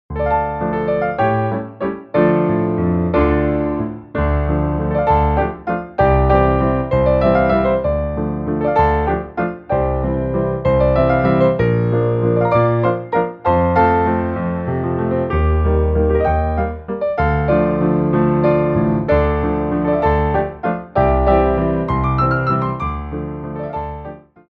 Grands Battements en Clôche
3/4 (8x8)